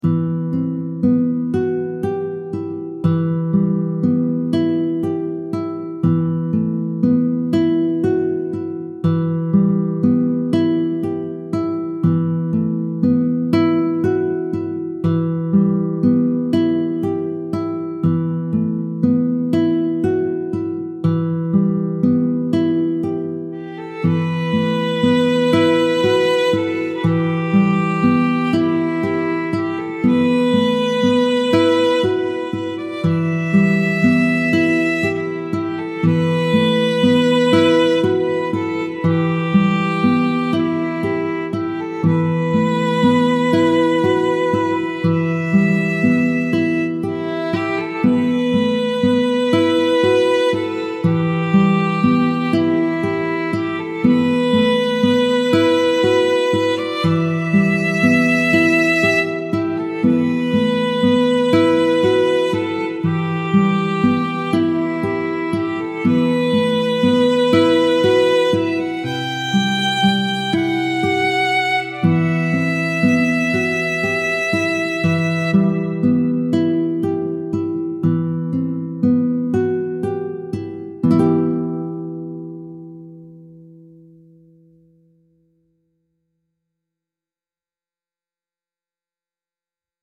classical guitar and violin duet in a baroque-inspired arrangement